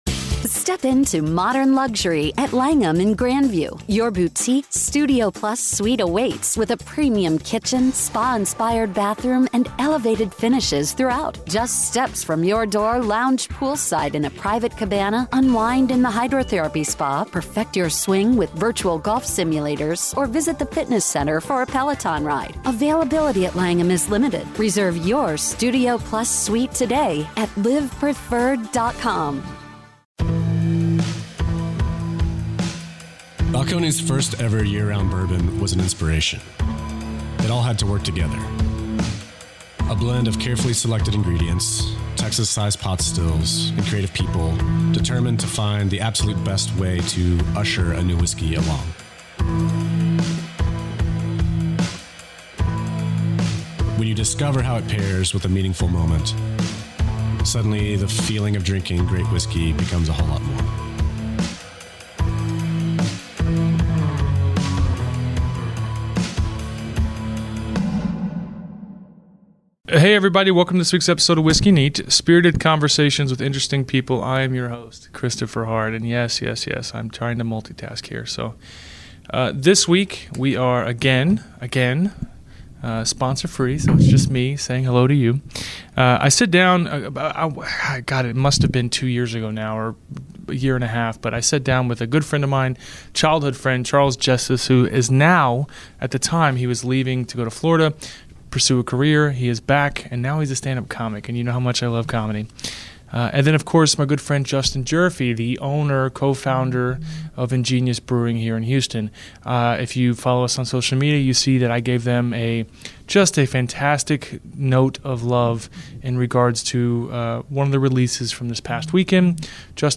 Whiskey Neat is a Radio show on iTunes and ESPN 97.5 FM in Houston and is brought to you every week by the following sponsors.